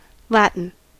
Ääntäminen
US : IPA : [ˈlæt.ən]